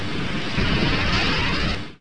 Rocket1.mp3